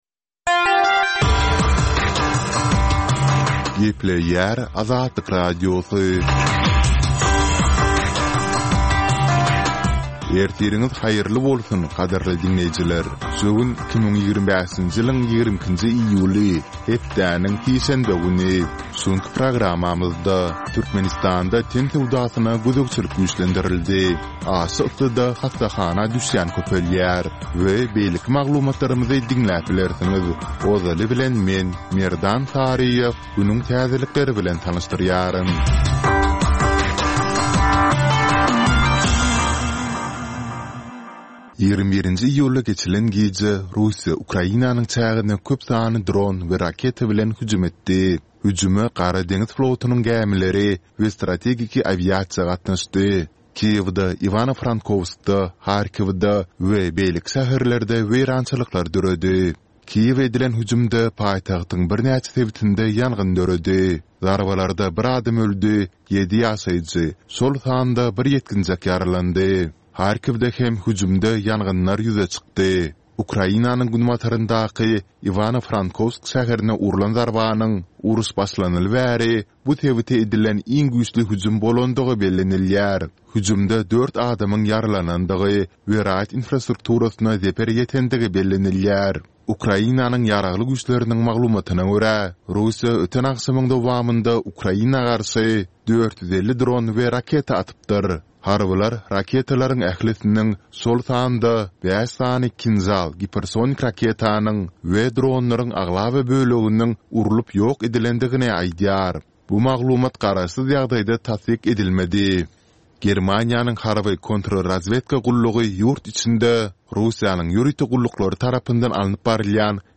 Täzelikler